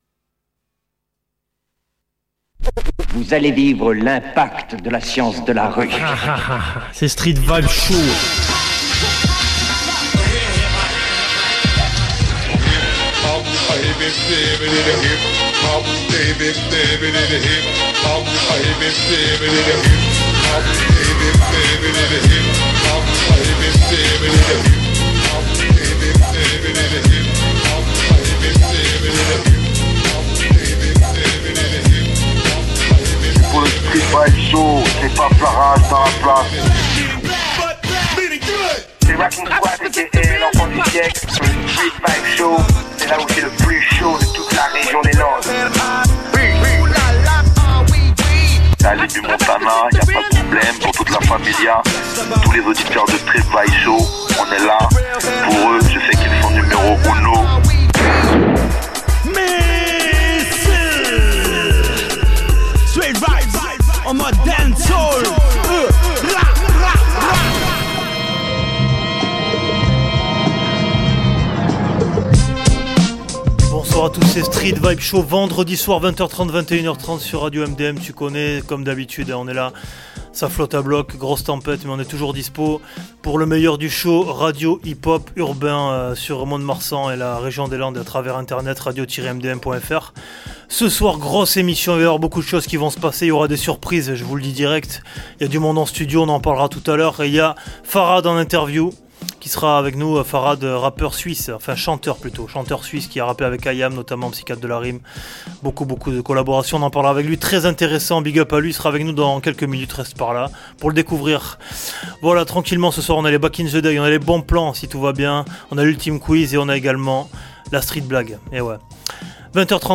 Un programme musical axé principalement sur les morceaux Soul et Rythm and Blues de la fin du XXème siècle.